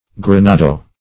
grenado - definition of grenado - synonyms, pronunciation, spelling from Free Dictionary Search Result for " grenado" : The Collaborative International Dictionary of English v.0.48: Grenado \Gre*na"do\, n. Same as Grenade .